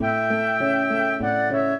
flute-harp
minuet10-3.wav